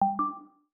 Game Invite.wav